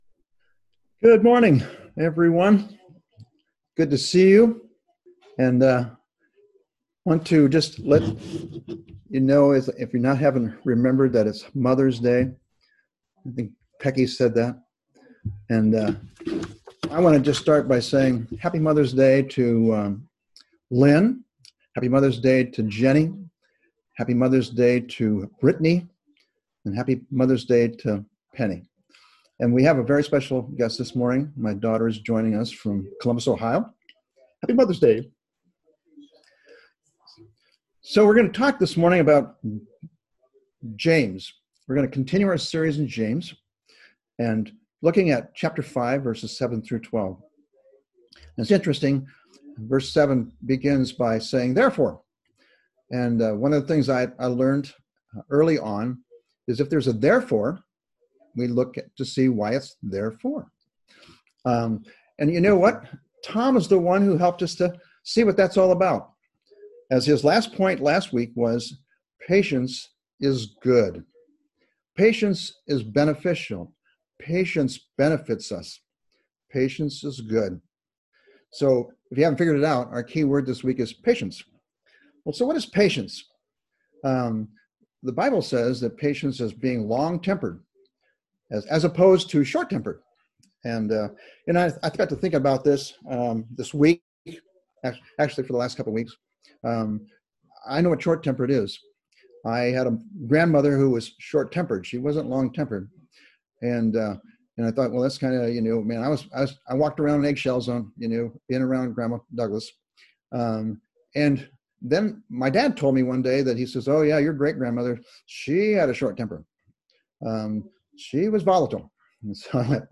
May 10, 2020 Abiding in Patience (05.10.2020) MP3 PDF SUBSCRIBE on iTunes(Podcast) Notes Discussion Sermons in this Series The key to perseverance in trials is patience from the Lord. God has shown us many examples of men of faith waiting patiently for God’s promise.